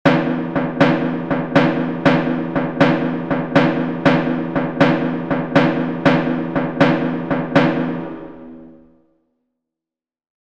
• SYRTOS: Danza griega en 4/4 que se toca como 3 + 3 + 2.
Audio de elaboración propia. Patrón rítmico Syrtos (CC BY-NC-SA)